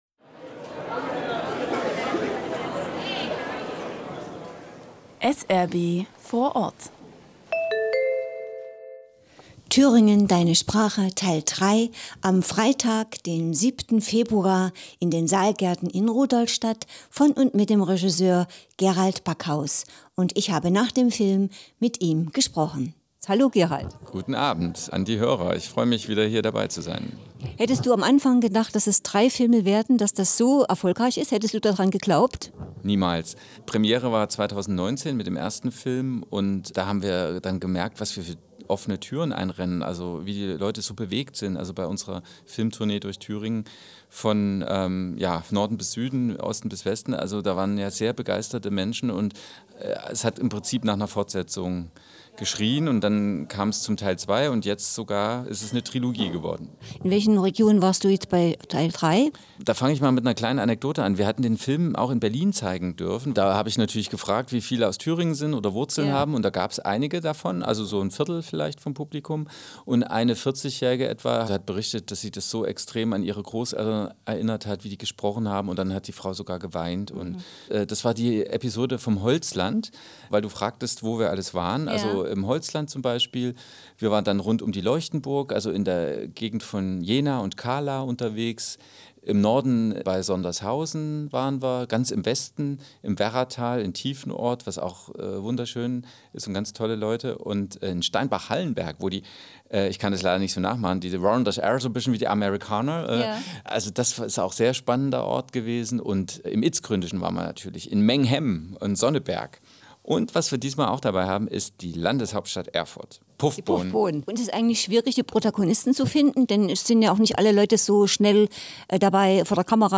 Radio-Interview SRB Rudolstadt/Saalfeld